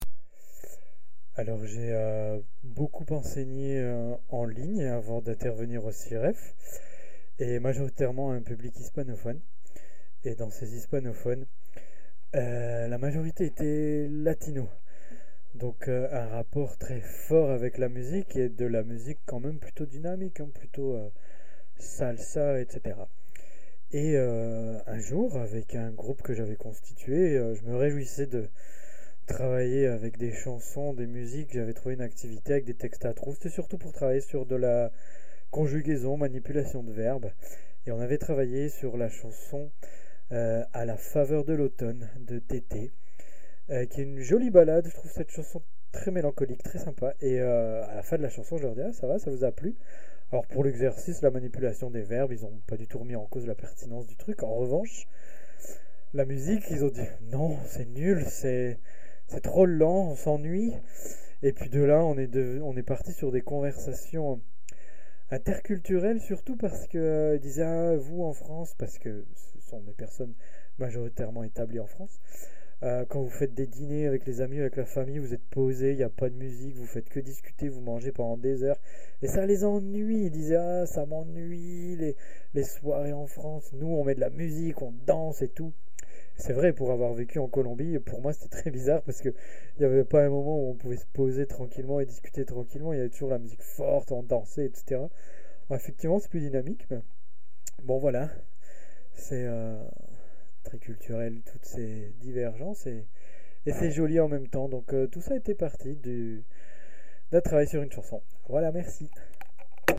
Cabine de témoignages
Témoignage du 20 novembre 2025 à 10h26